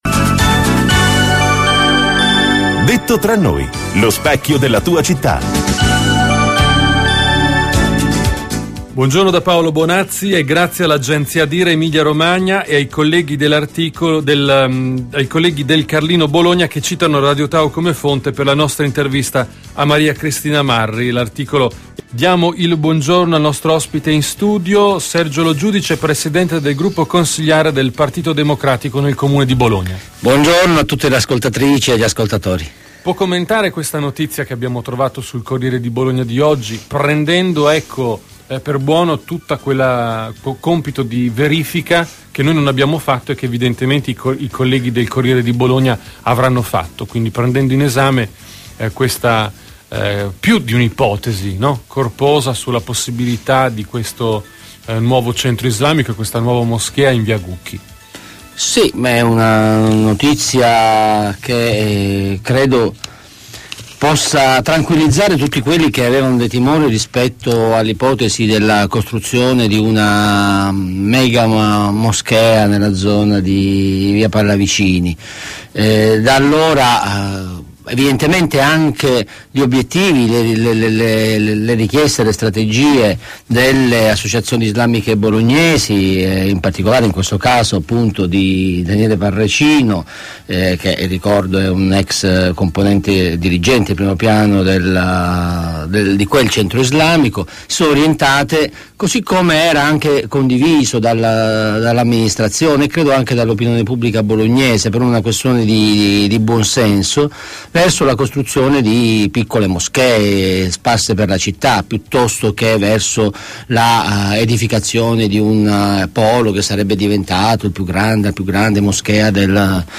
Intervista a Radio Tau del capogruppo PD Sergio Lo Giudice 24 gennaio 2012